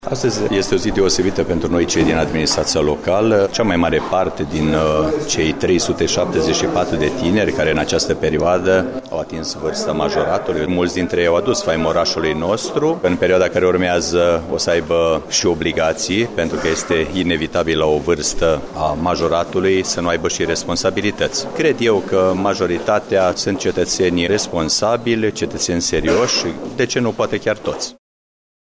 Mihai Stepanescu, primarul municipiului Reşiţa: